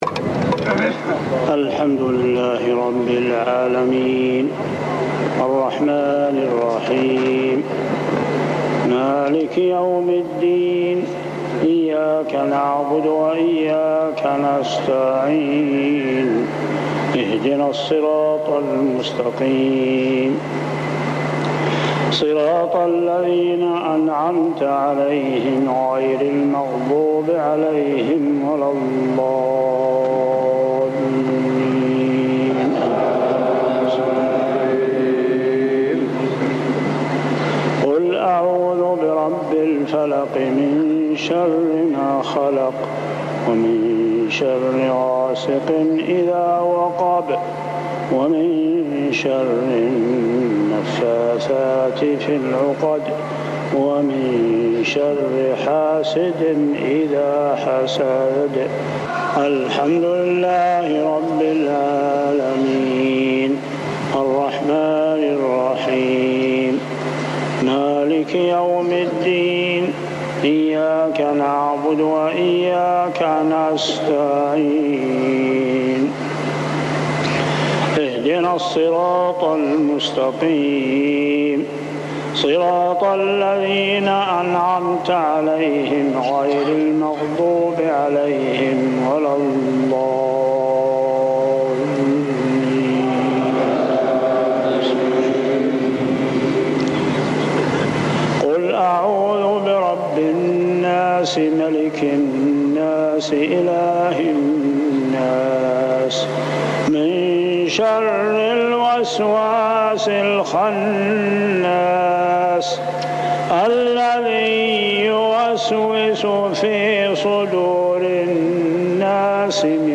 صلاة المغرب 9-9-1402هـ سورتي الفلق و الناس كاملة | maghrib prayer Surah Al-Falaq and An-Nas > 1402 🕋 > الفروض - تلاوات الحرمين